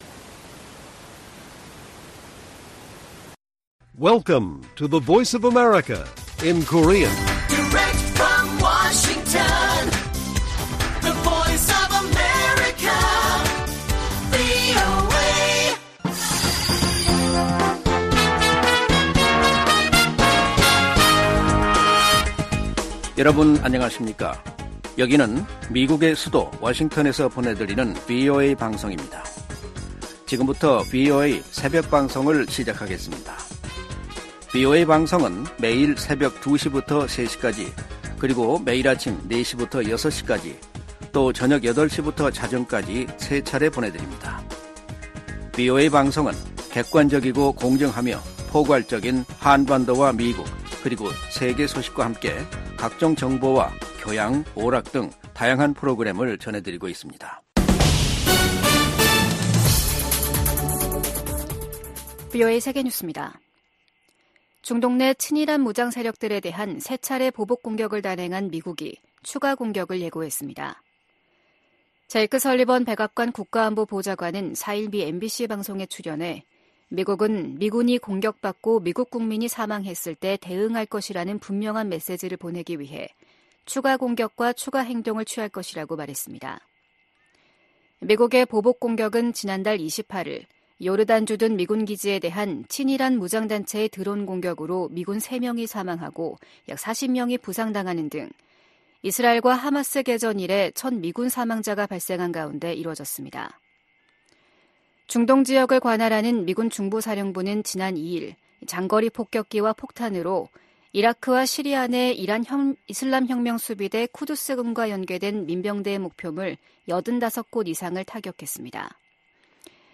VOA 한국어 '출발 뉴스 쇼', 2024년 2월 6일 방송입니다. 북한은 순항미사일 초대형 전투부 위력 시험과 신형 지대공 미사일 시험발사를 지난 2일 진행했다고 대외 관영 ‘조선중앙통신’이 다음날 보도했습니다. 미국 정부는 잇따라 순항미사일을 발사하고 있는 북한에 도발을 자제하고 외교로 복귀하라고 촉구했습니다. 미국 정부가 미국내 한인 이산가족과 북한 가족들의 정보를 담은 기록부를 구축하도록 하는 법안이 하원에서 발의됐습니다.